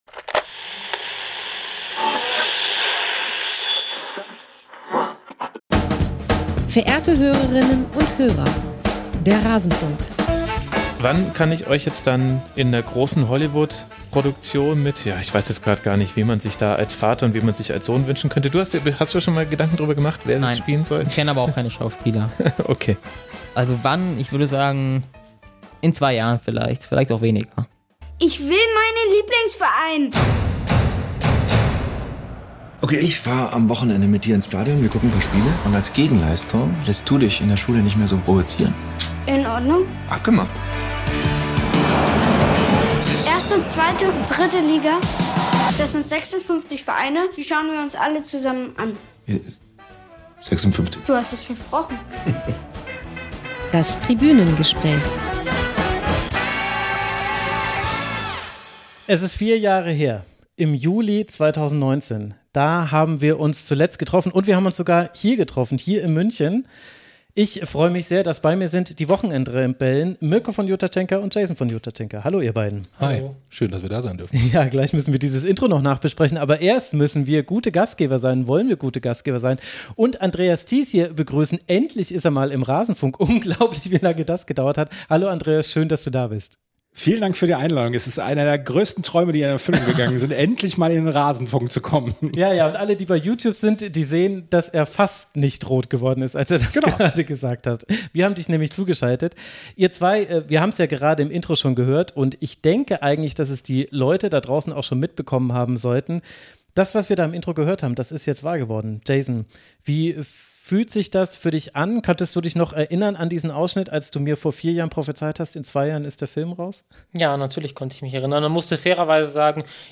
Interview mit den Hauptdarsteller*innen